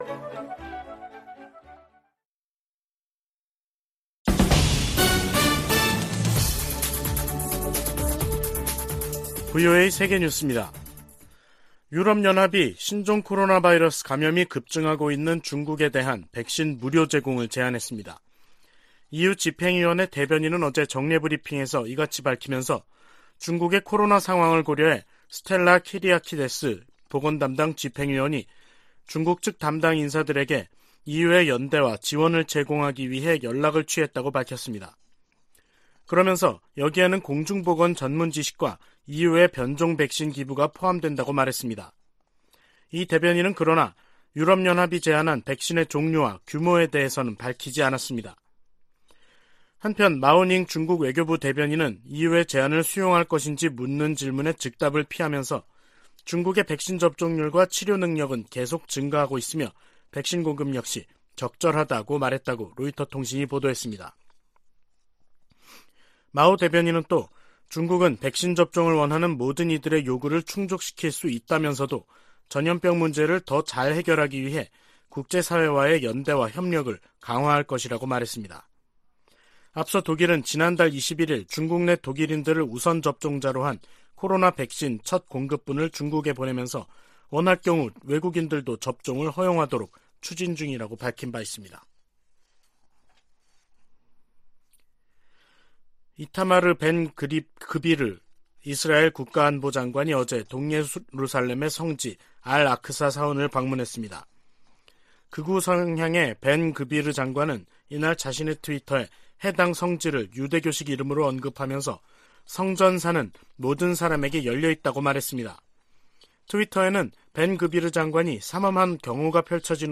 VOA 한국어 간판 뉴스 프로그램 '뉴스 투데이', 2023년 1월 4일 2부 방송입니다. 미국 백악관은 미국과 한국 두 나라가 북한 핵 사용 가능성에 대한 대응 방안을 논의 중이라고 확인했습니다. 윤석열 한국 대통령은 북한이 다시 한국 영토를 침범할 경우 9.19 남북 군사합의 효력 정지를 검토하라고 지시했습니다.